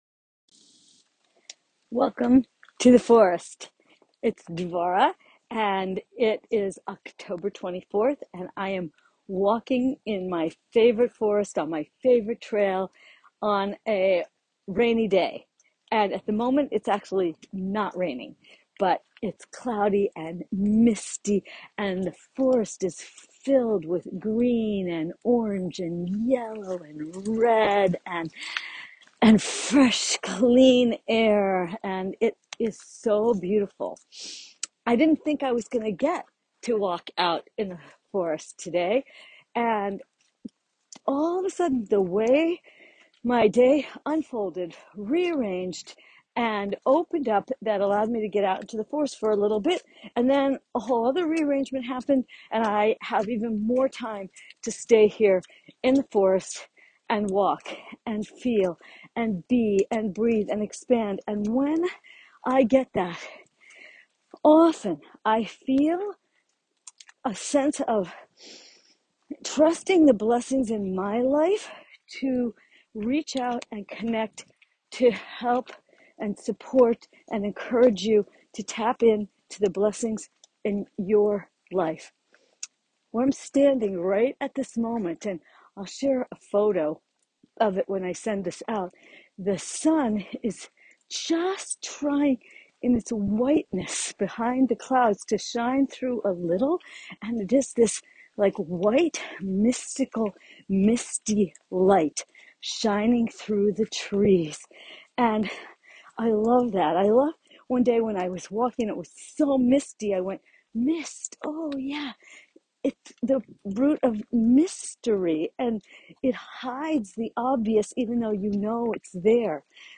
I am standing in the forest in my favorite spot in the rain and it is so beautiful. I’m in the mist and the mystery and the mysterious that is wondrous! And standing to receive a blessing from the trees!
Audio Message!